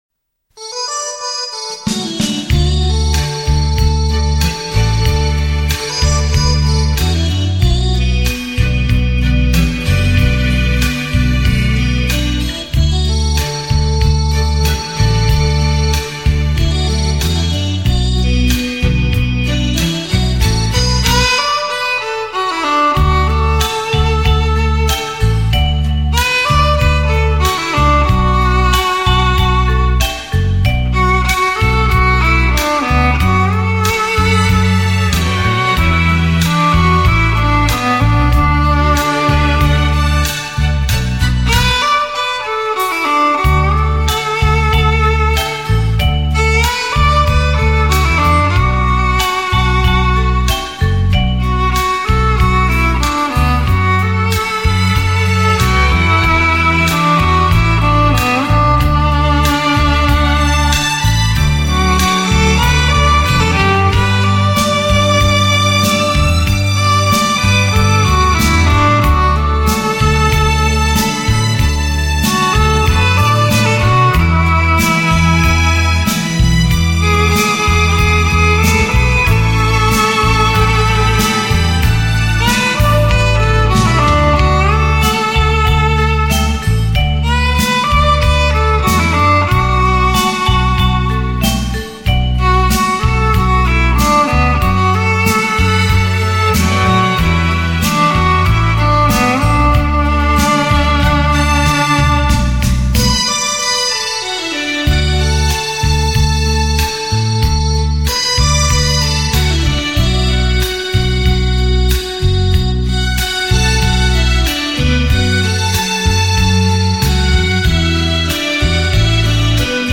名家演绎小提琴名曲